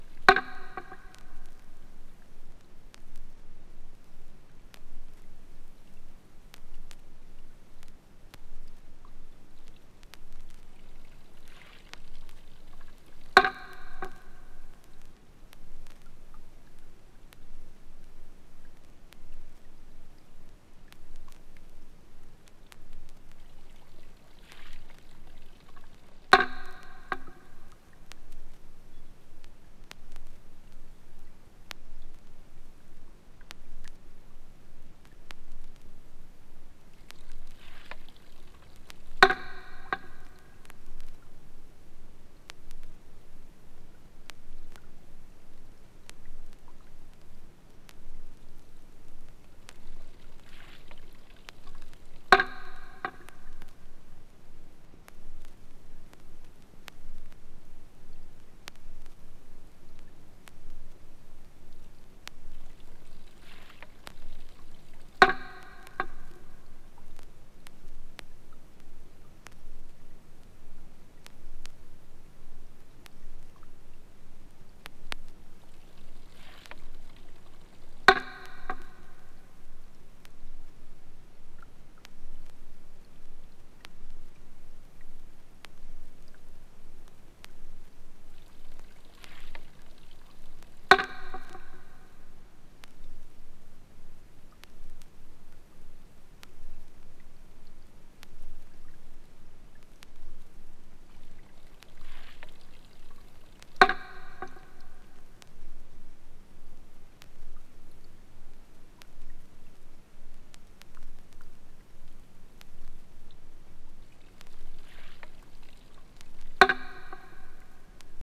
2. > ROCK/POP
伝統的な日本庭園の音をテーマにしたコンセプチュアル・アルバム